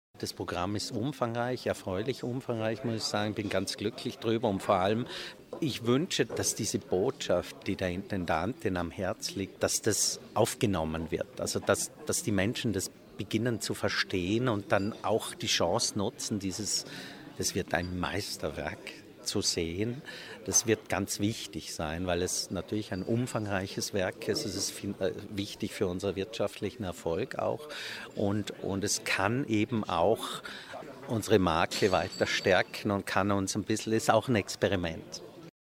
Programmpräsentation 2015 - O-Ton news